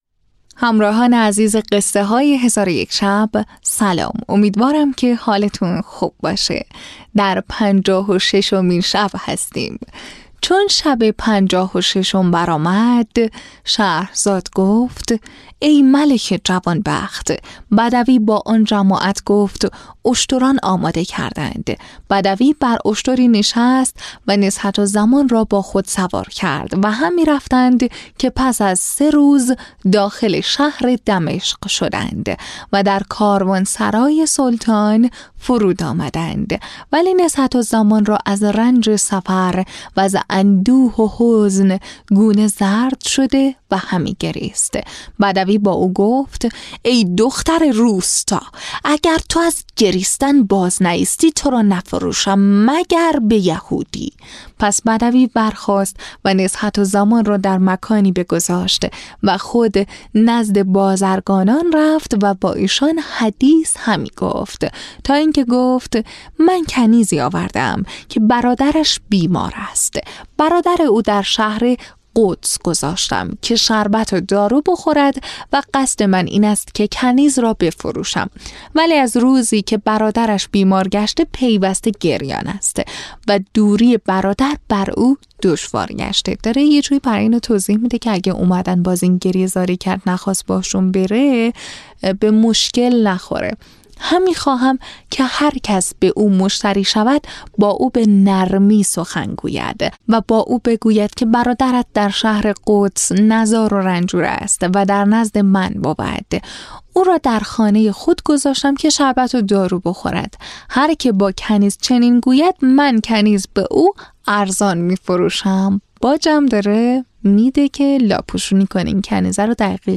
تهیه شده در استودیو نت به نت